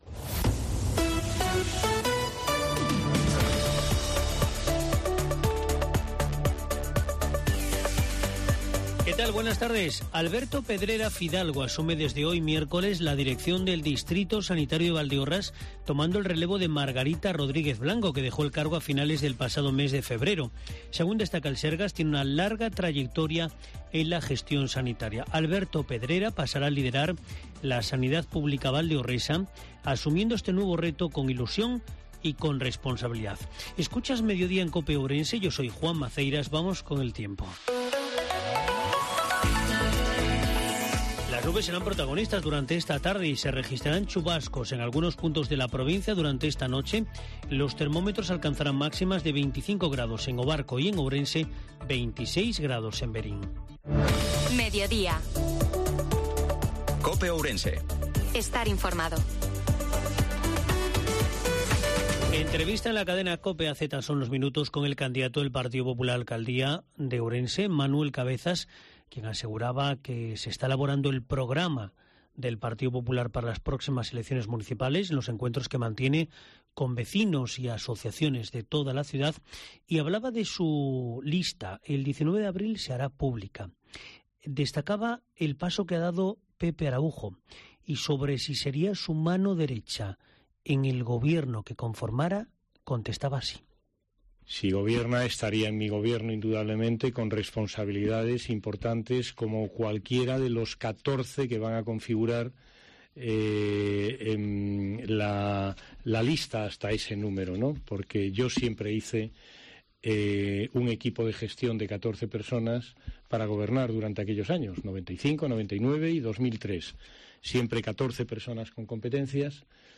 INFORMATIVO MEDIODIA COPE OURENSE-29/03/2023